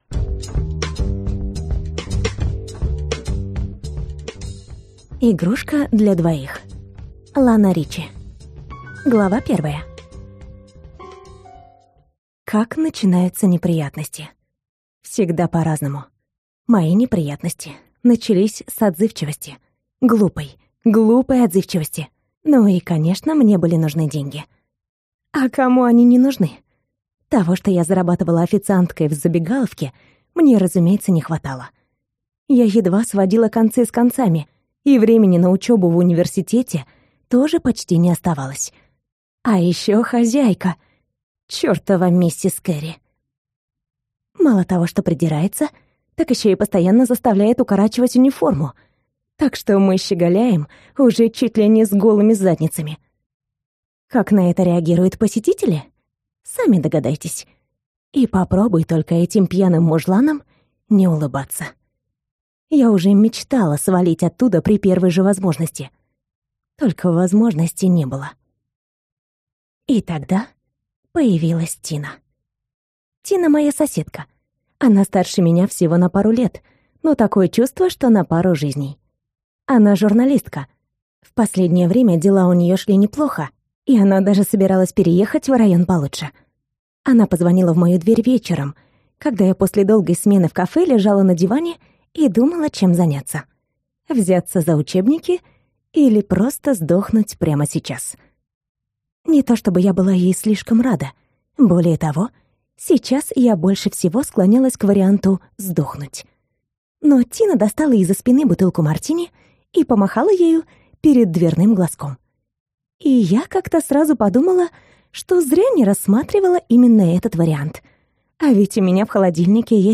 Аудиокнига Игрушка для двоих | Библиотека аудиокниг